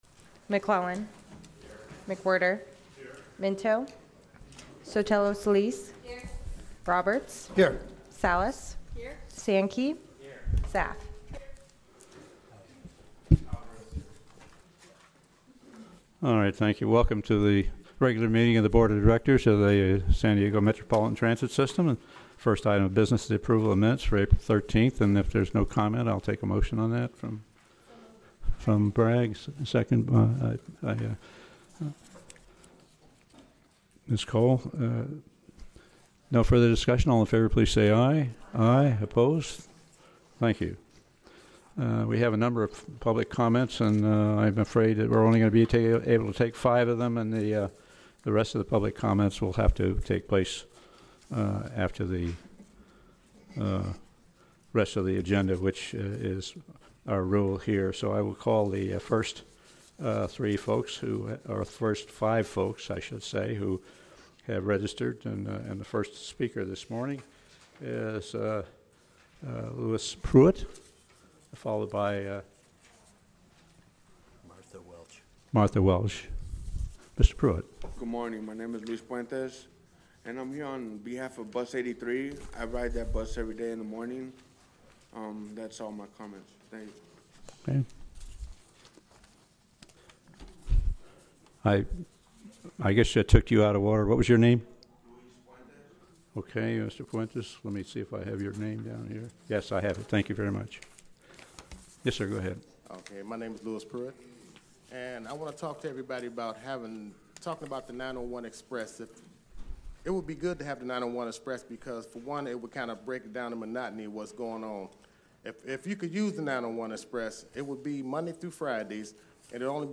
Public Hearing - Proposed FY 18 Budget
Board Meeting